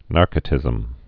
(närkə-tĭzəm)